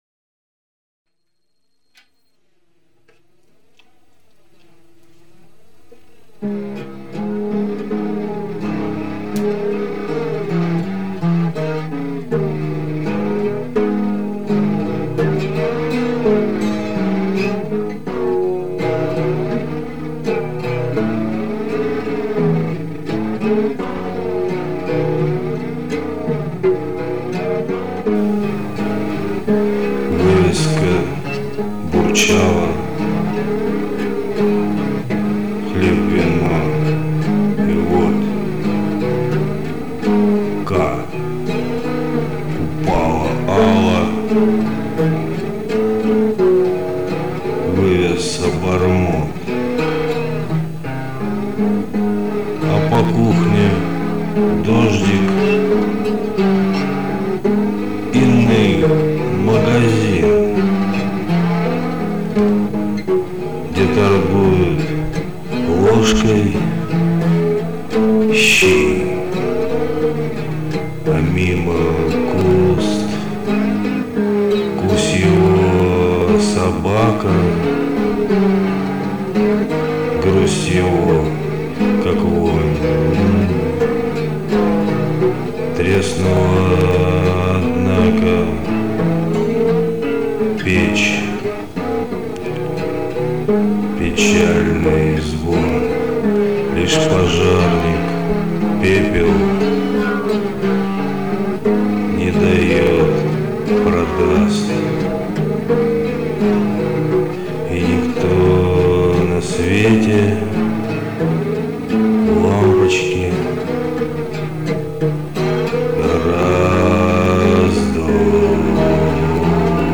Бротан, это не рок, это грязная бардовская песня :) ты потом записанное то обрабатываешь?
Я конечно хз - слушал с телефона в наушниках, но качество ужасное.